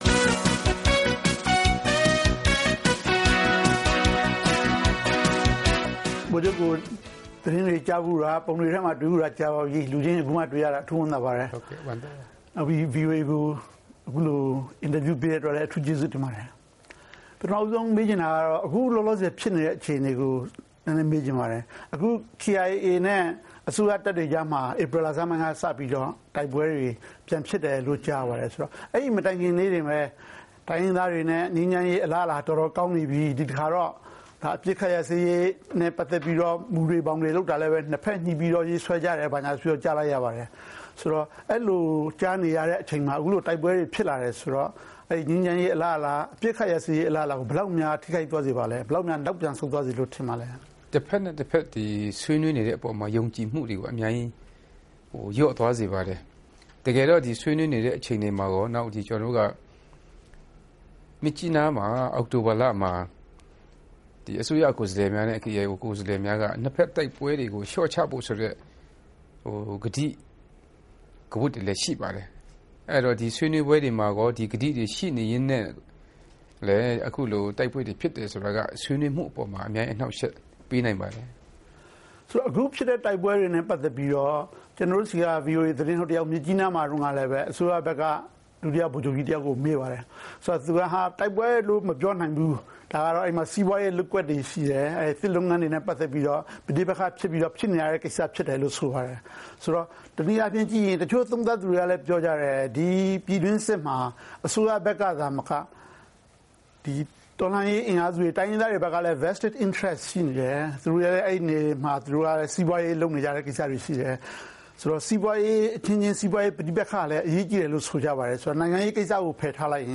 ဗိုလ်ချုပ်ဂွမ်မော်နဲ့ တွေ့ဆုံမေးမြန်းခန်း